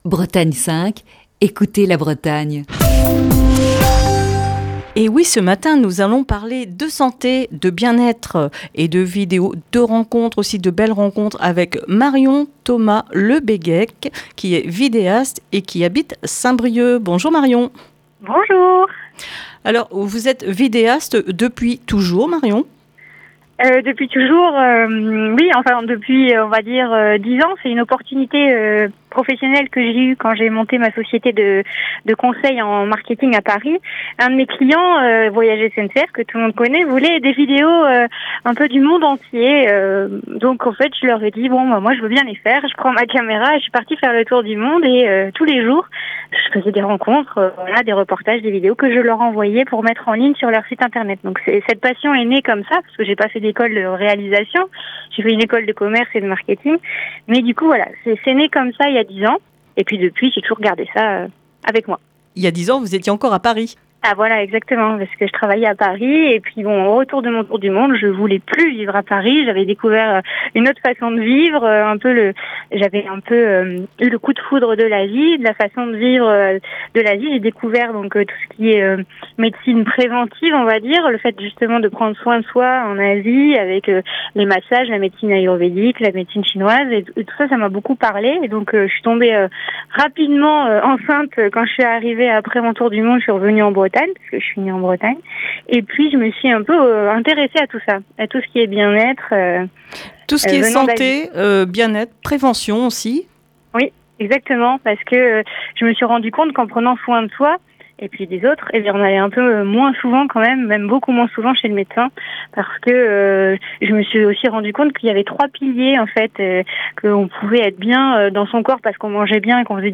Ce mercredi dans le coup de fil du matin